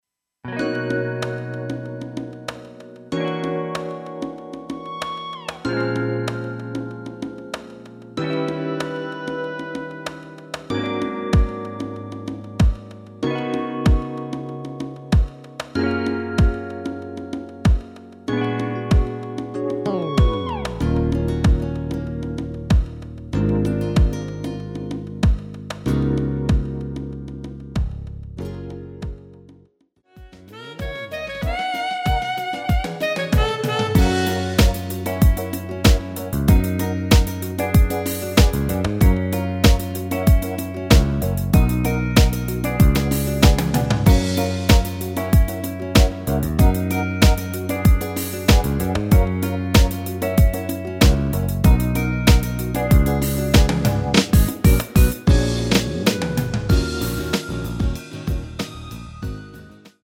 F#
앞부분30초, 뒷부분30초씩 편집해서 올려 드리고 있습니다.